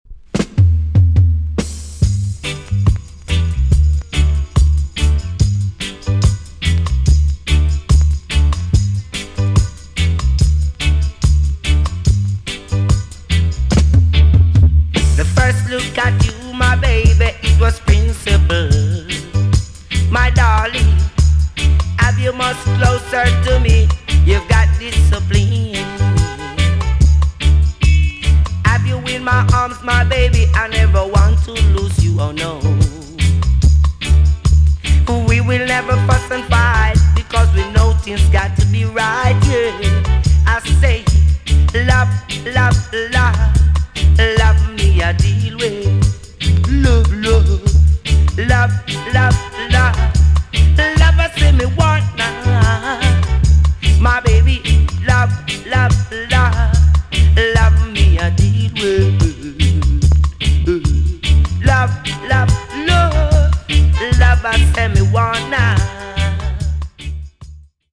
TOP >REGGAE & ROOTS
EX- 音はキレイです。
1980 , NICE VOCAL TUNE!!